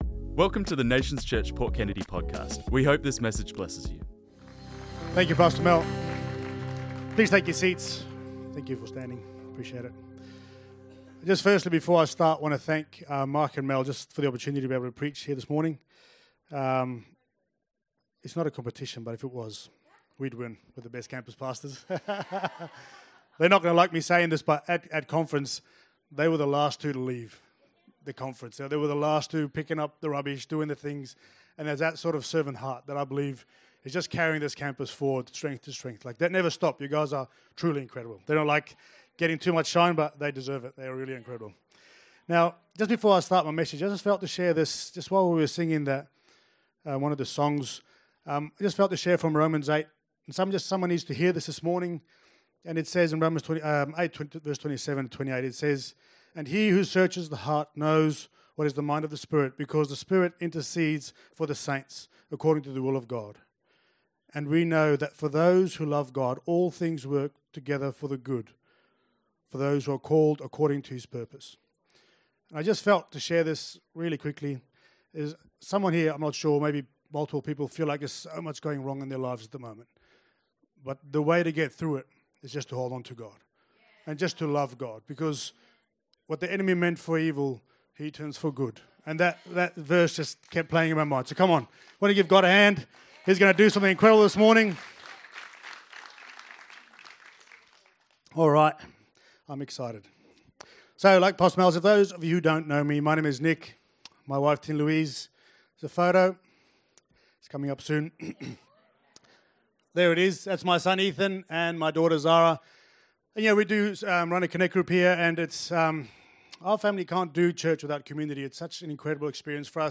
This message was preached on Sunday the 10th of August 2025.